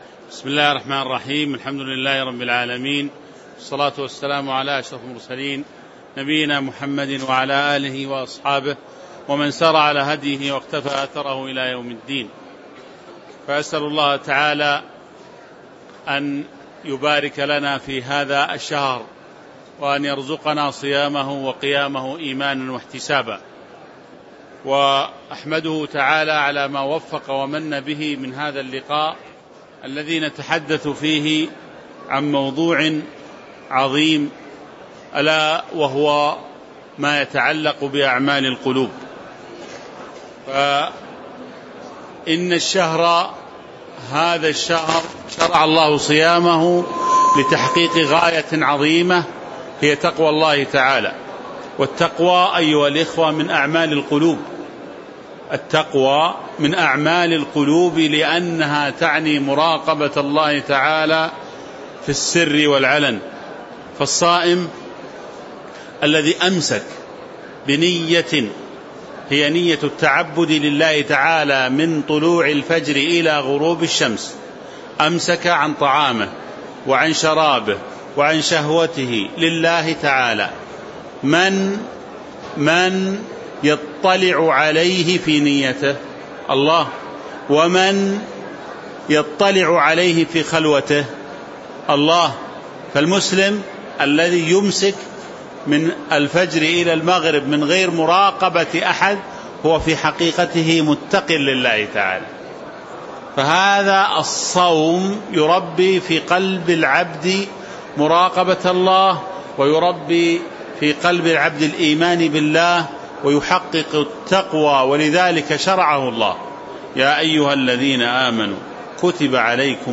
تاريخ النشر ٢ رمضان ١٤٤٦ هـ المكان: المسجد النبوي الشيخ